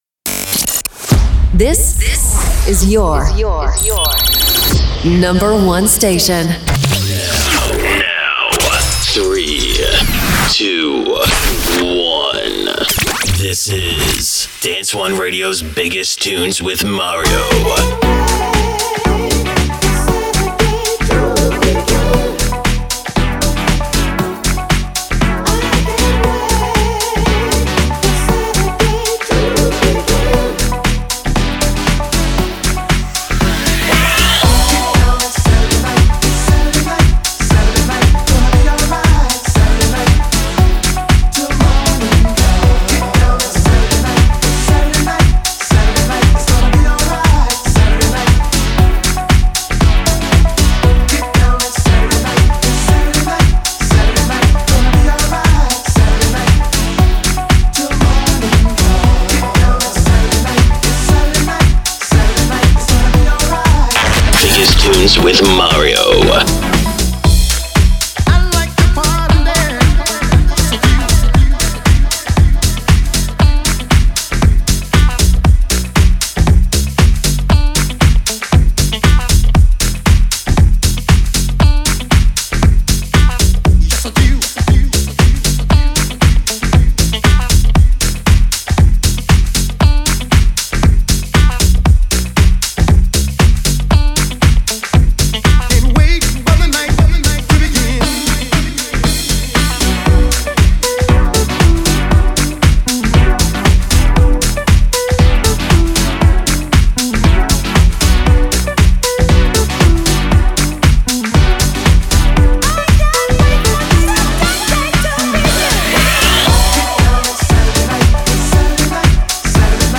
Dance weapons that rule the dance and electronic scene